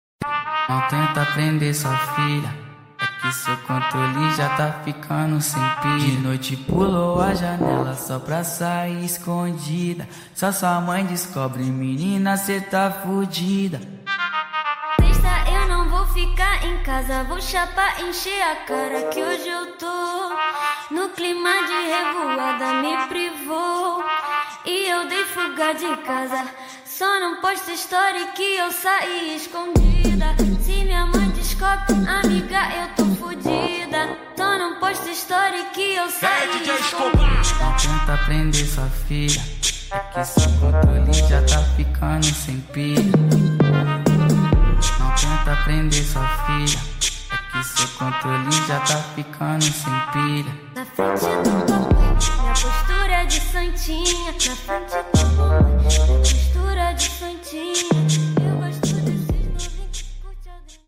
8D 🎧🎶